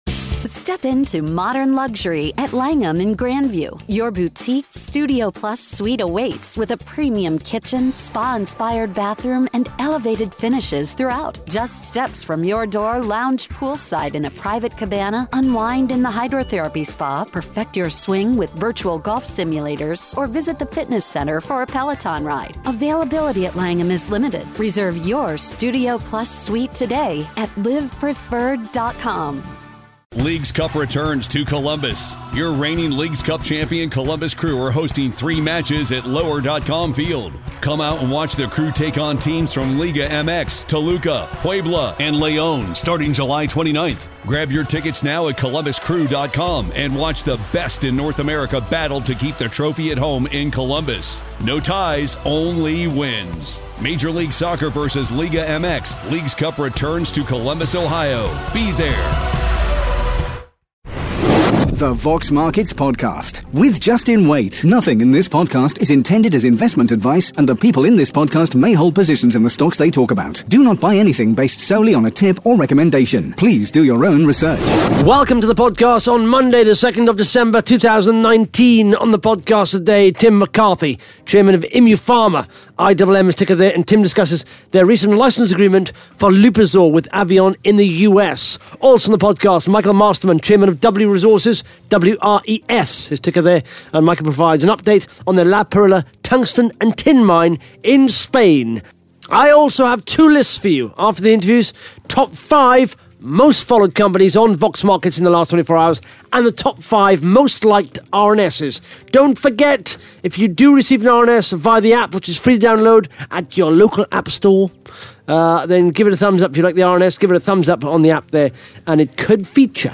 (Interview starts at 13 minutes 53 seconds) Plus the Top 5 Most Followed Companies & the Top 5 Most Liked RNS’s on Vox Markets in the last 24 hours.